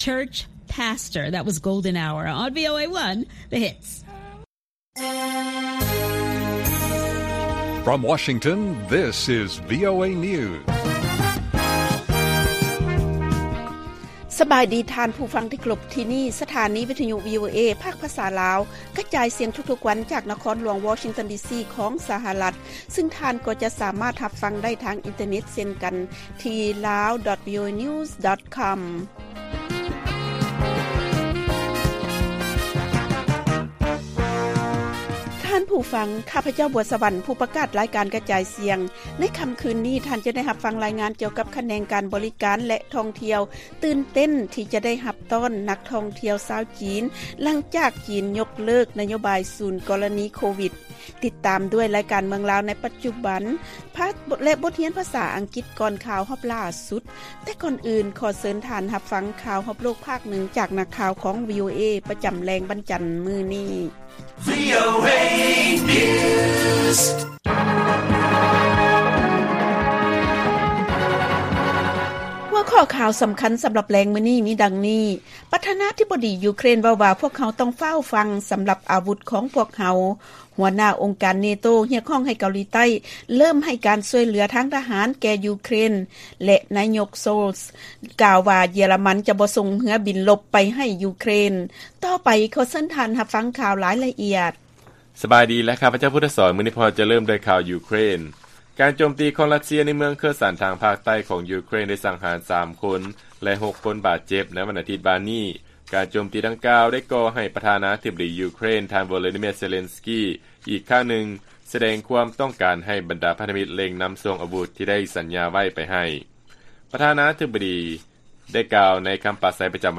ລາຍການກະຈາຍສຽງຂອງວີໂອເອ ລາວ: ປະທານາທິບໍດີ ຢູເຄຣນ ເວົ້າວ່າ "ພວກເຮົາຕ້ອງຟ້າວຝັ່ງ" ສຳລັບອາວຸດຂອງພວກເຮົາ